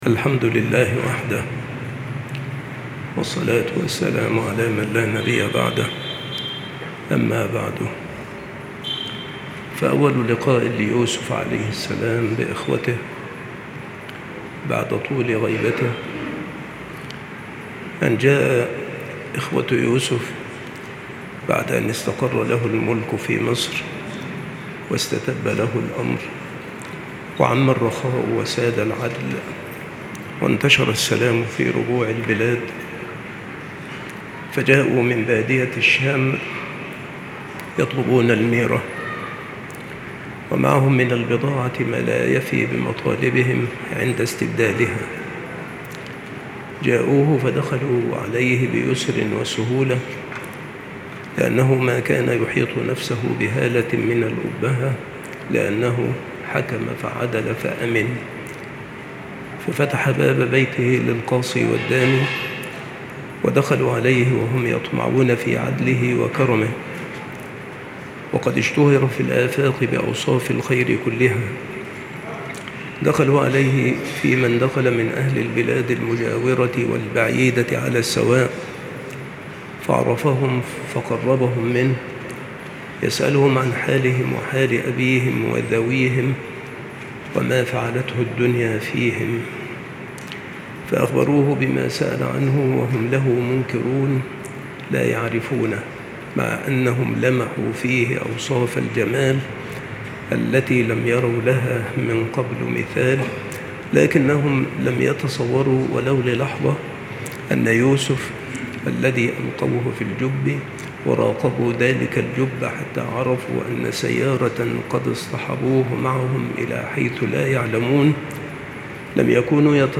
التصنيف قصص الأنبياء
مكان إلقاء هذه المحاضرة بالمسجد الشرقي - سبك الأحد - أشمون - محافظة المنوفية - مصر